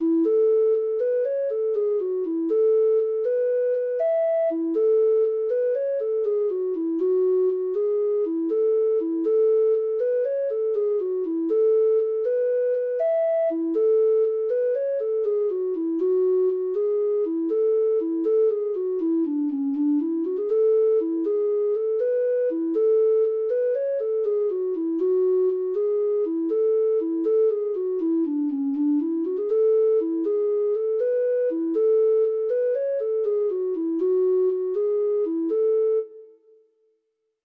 Free Sheet music for Treble Clef Instrument
Traditional Music of unknown author.
Irish Slip Jigs